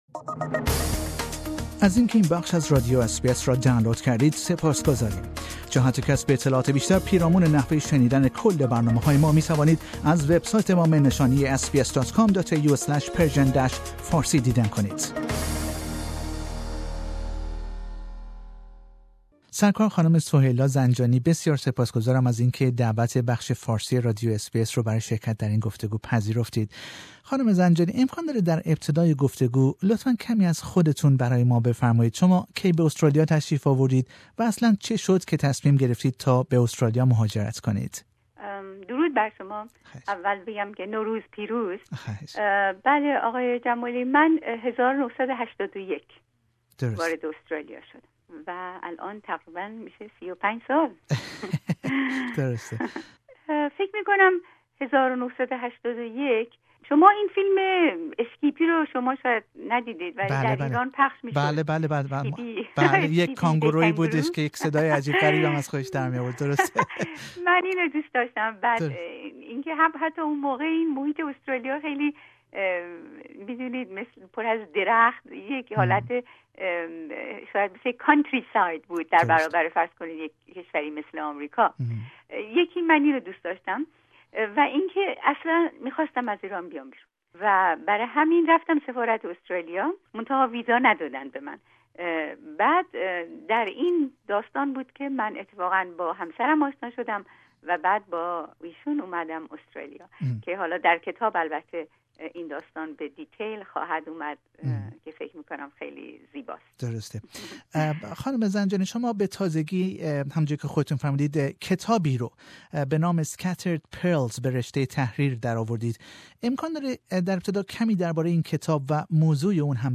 In an interview with SBS Radio's Persian Program